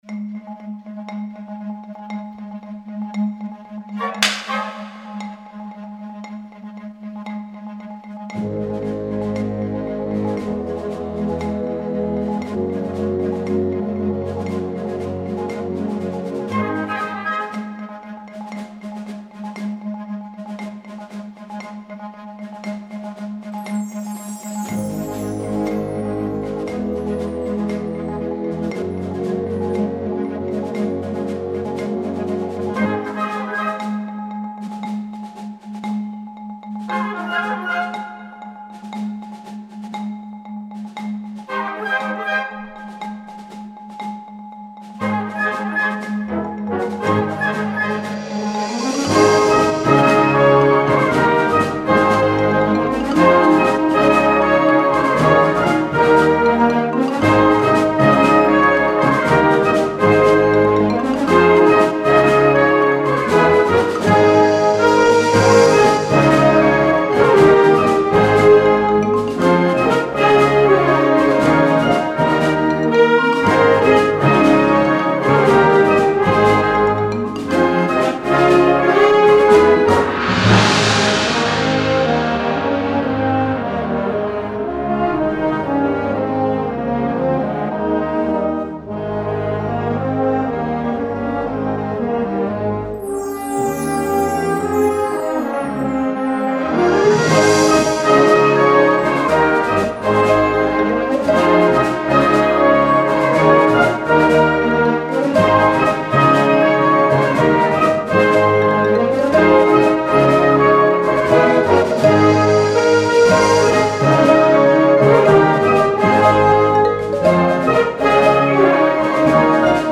Instrumentation: Blasorchester
Sparte: Popularmusik